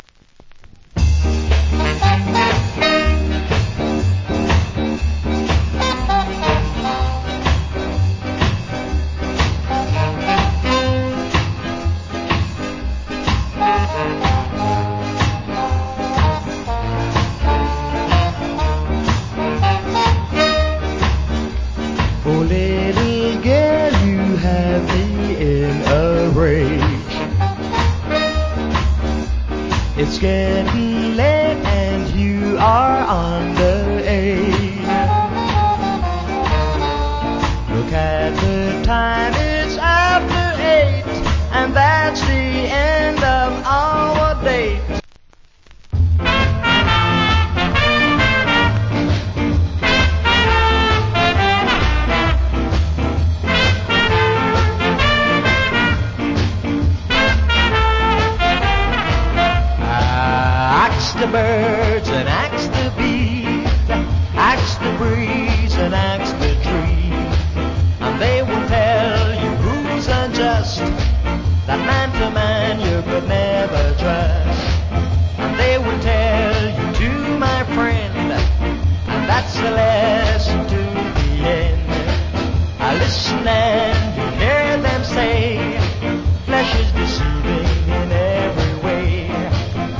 Great Ska Vocal. / Nice Ska Vocal.